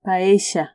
paella /paesha/